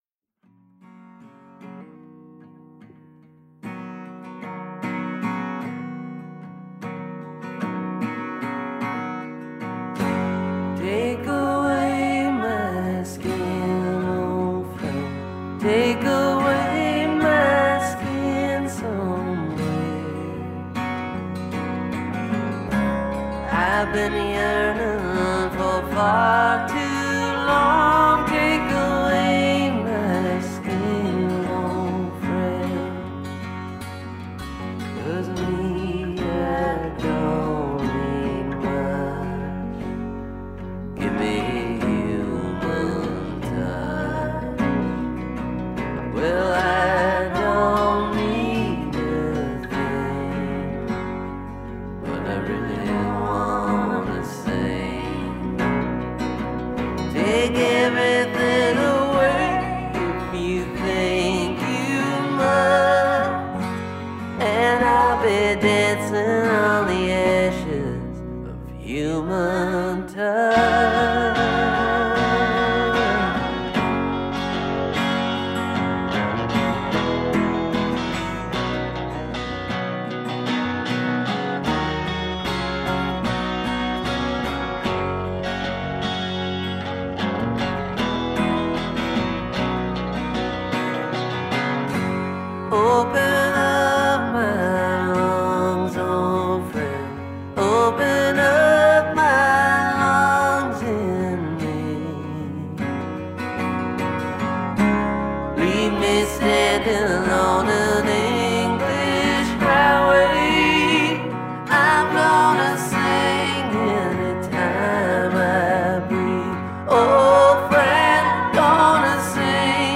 a more muted folk and country effort